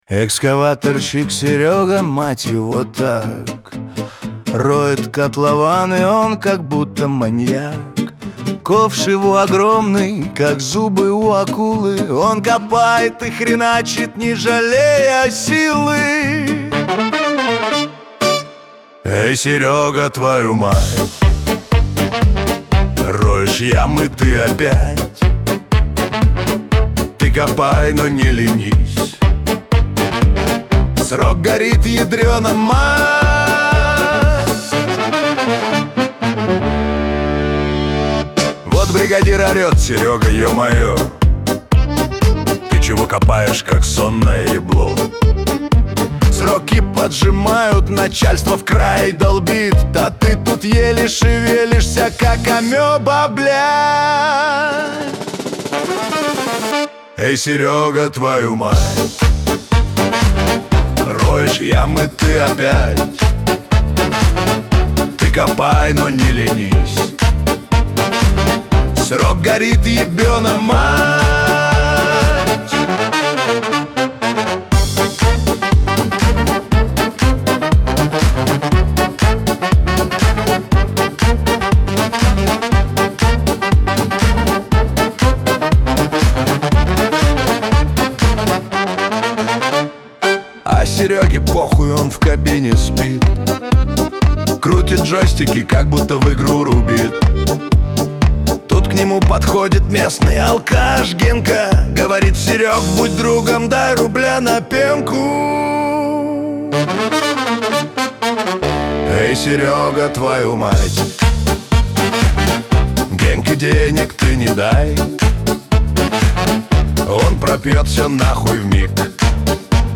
весёлая музыка
Шансон